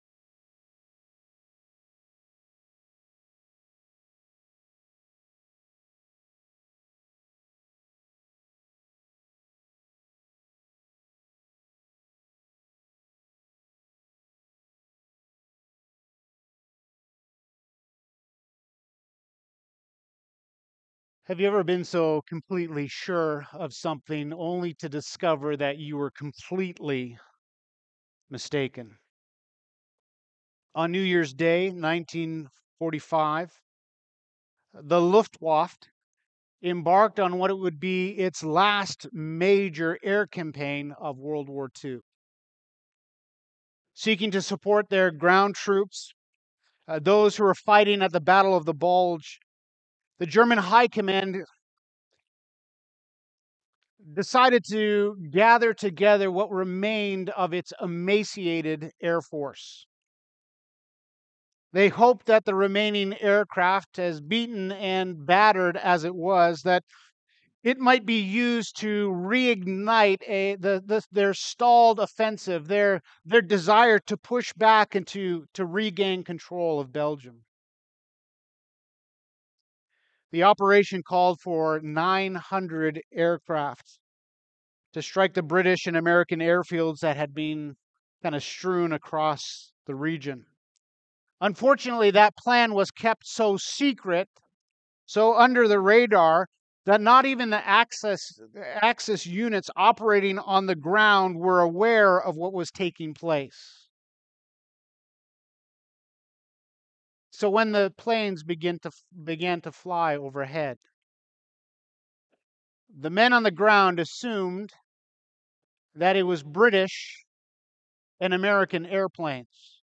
Sermons - Grace Bible Fellowship